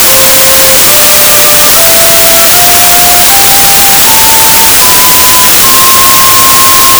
flauta_noise.wav